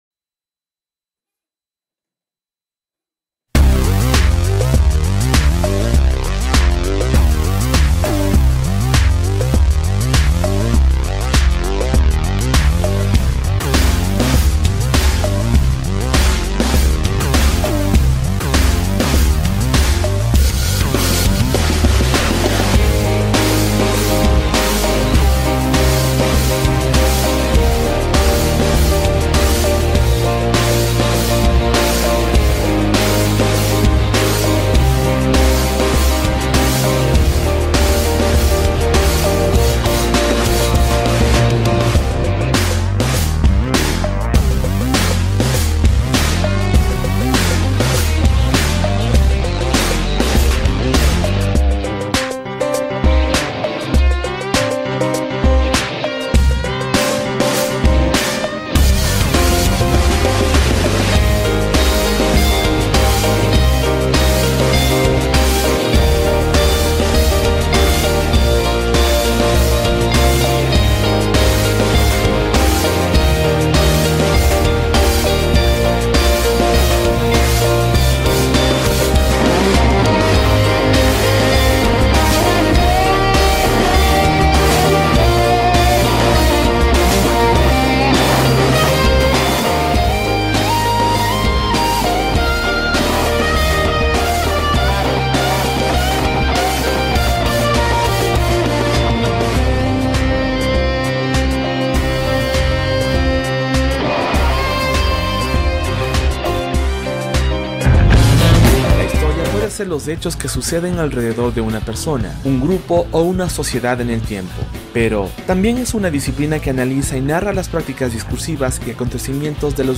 Escucha en la entrevista completam esta apasionante historia.